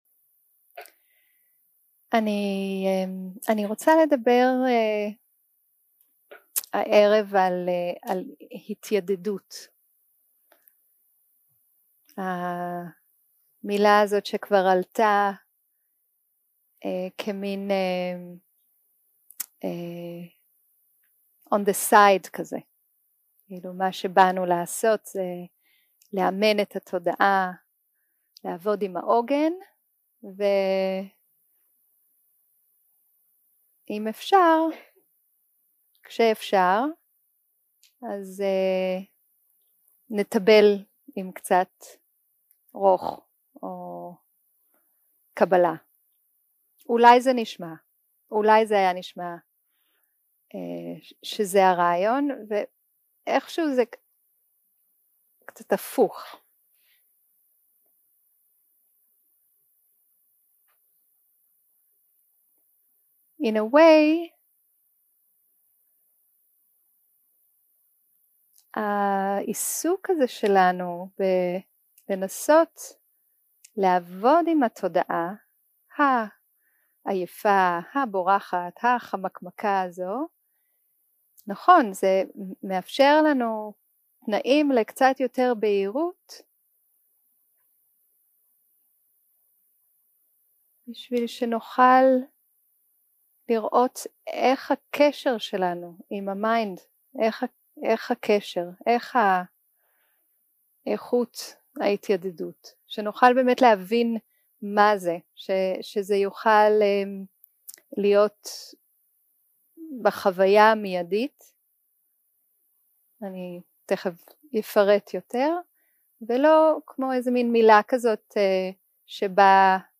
יום 2 - הקלטה 4 - ערב - שיחת דהרמה - התיידדות
Dharma Talks